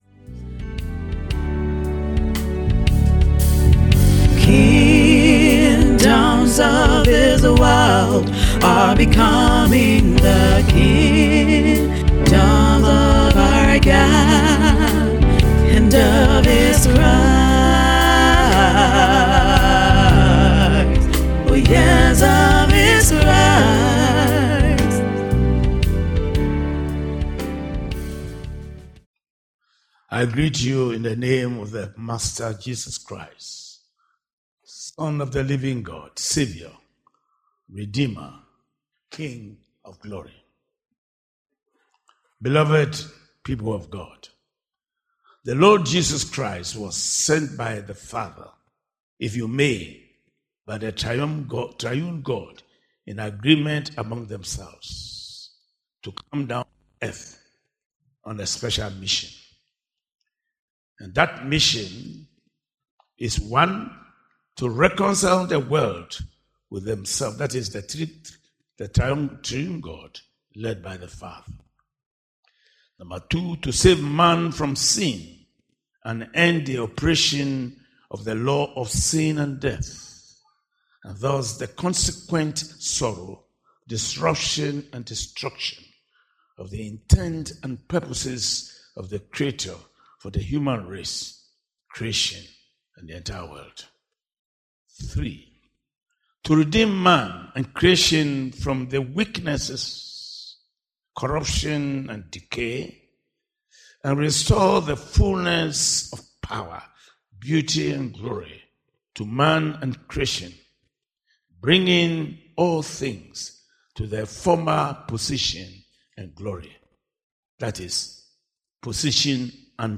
SERMON TITLE: The three golden crowns